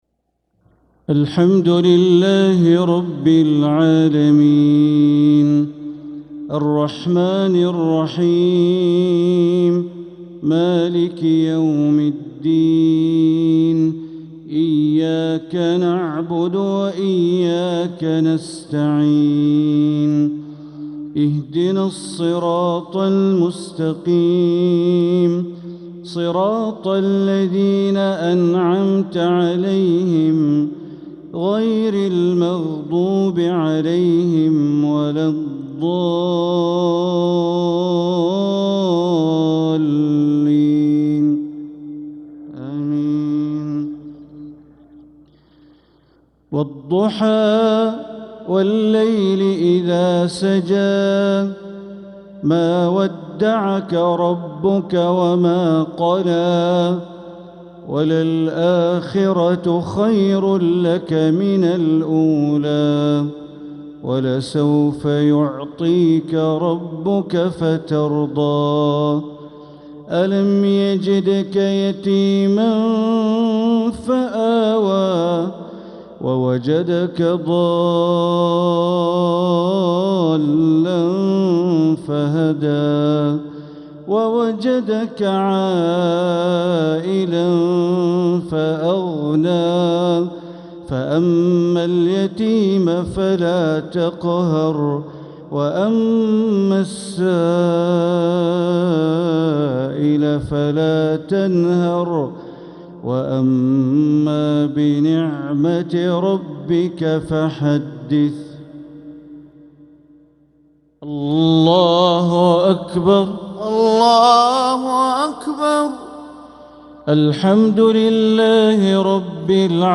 Maghrib prayer Surat ad-Duha & ash-Shart 6-2-2025 > 1446 > Prayers - Bandar Baleela Recitations